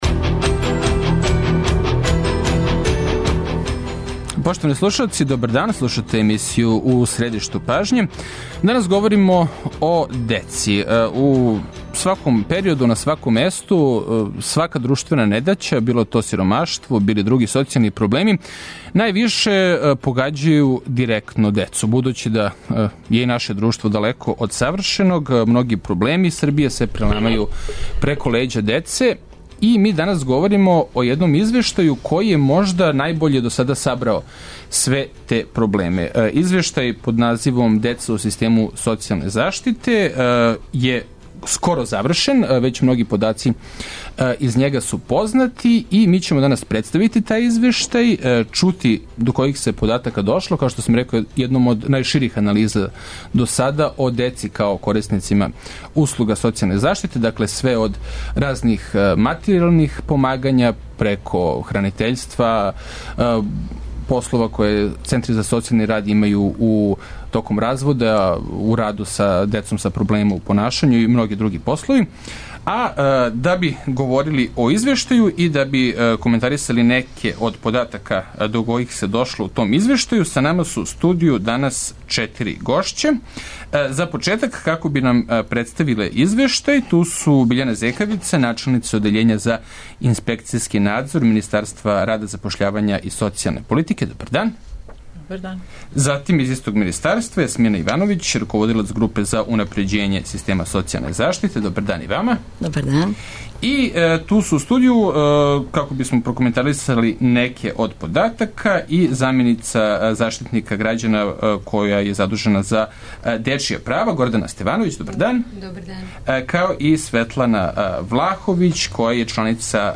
Радио Београд 1, 17.05